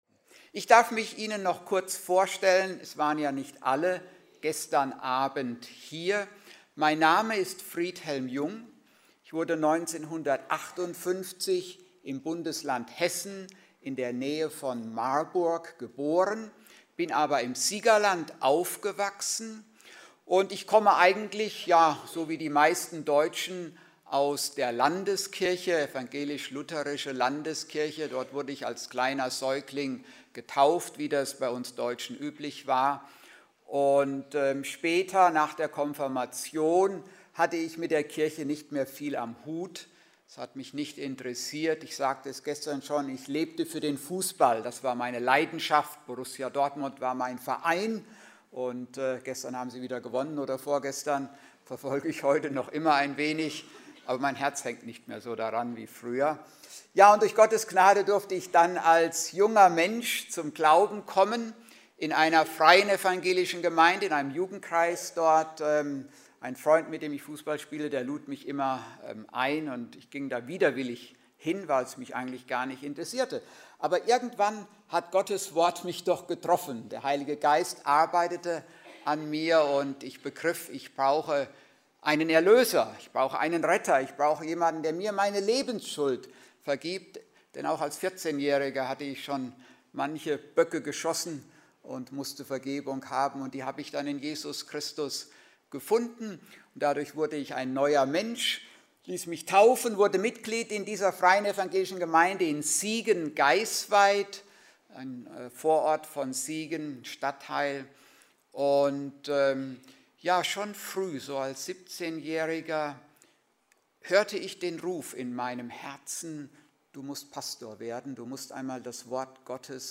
doch nicht von dieser Welt Prediger